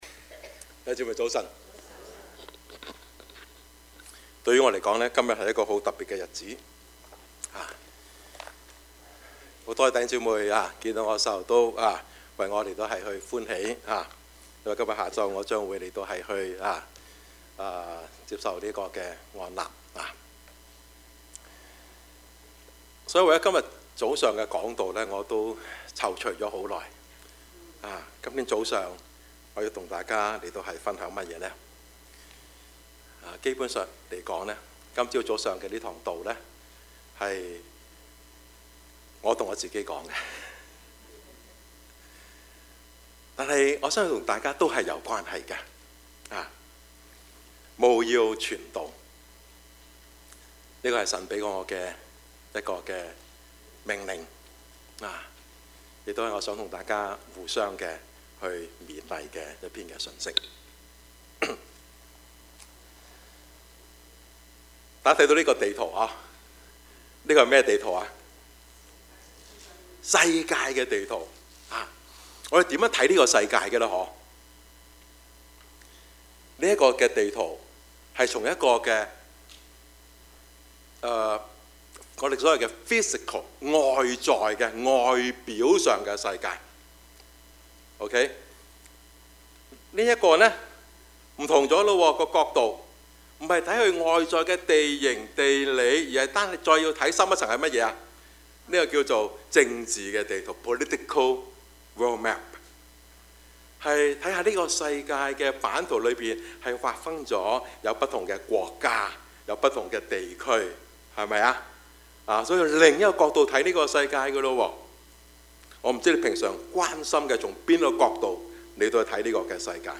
Topics: 主日證道 « 烈火同行 無能者的大能 »